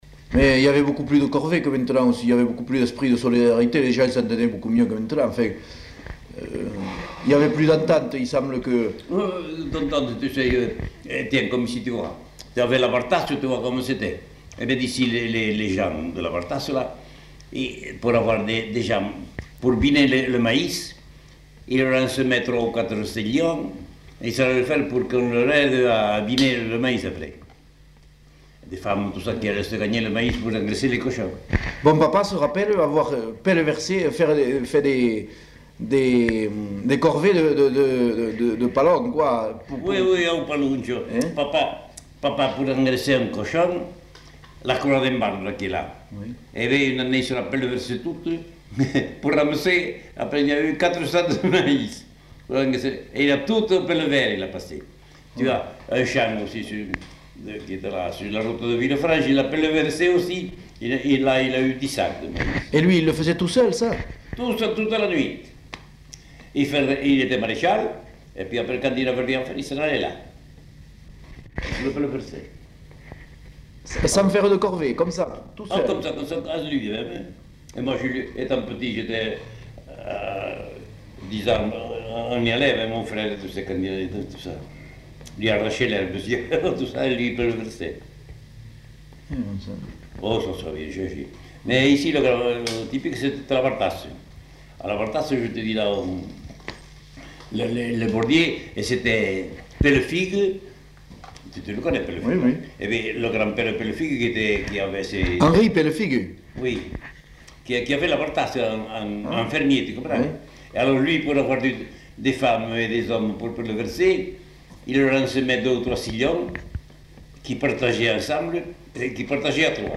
Aire culturelle : Savès
Genre : témoignage thématique